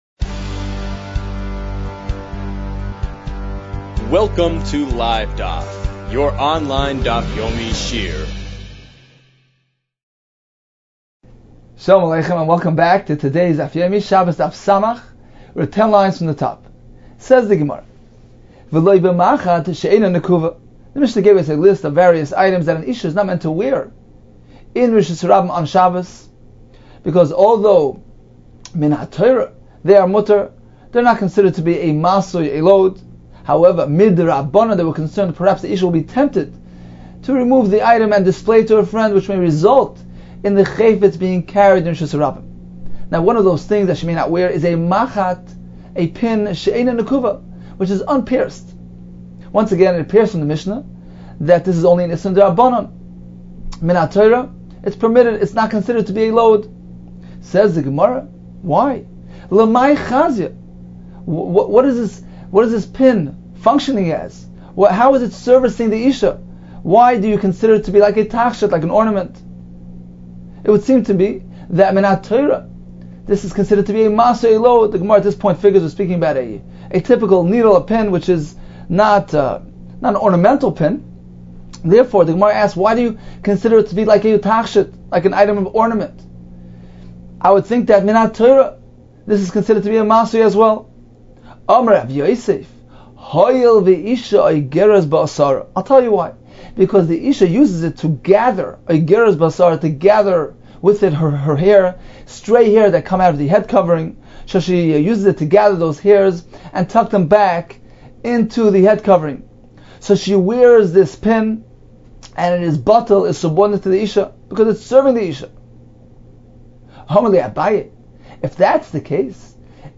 Shabbos 60 - 'שבת ס | Daf Yomi Online Shiur | Livedaf